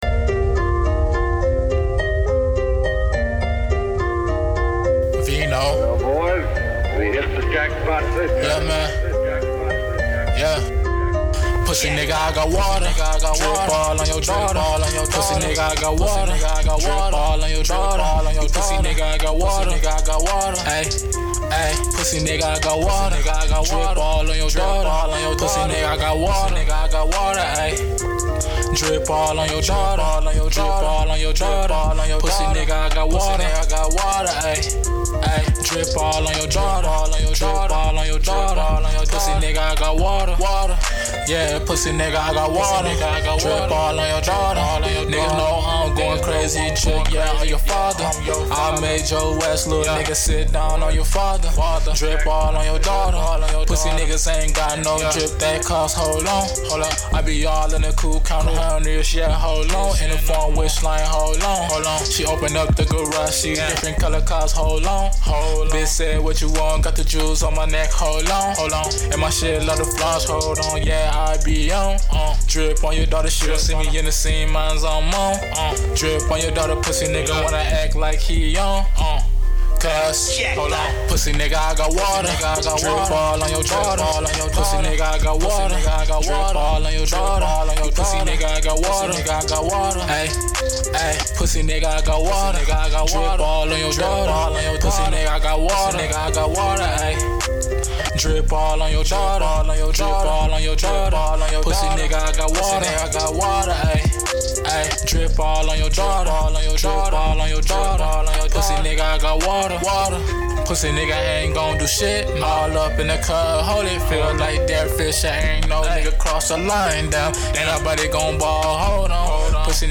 R&B, HipHop, Trap, & Melodic Poject!